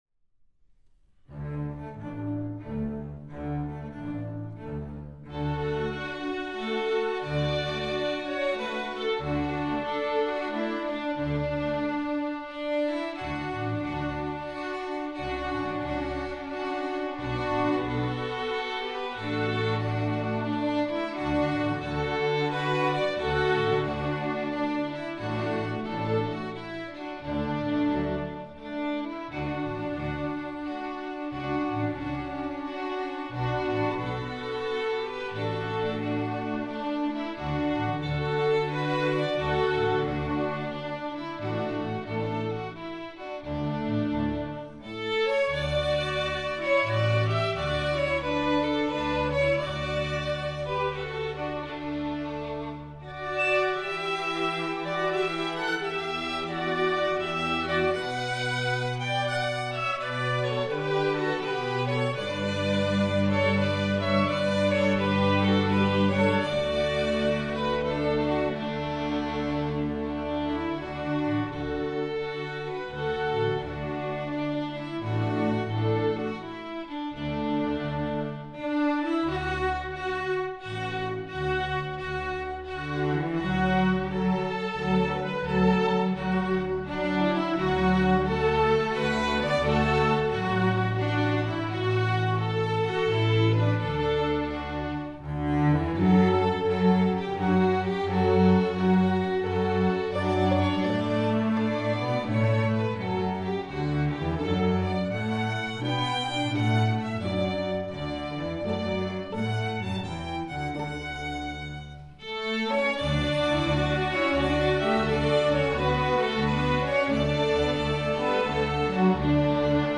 Instrumentation: string orchestra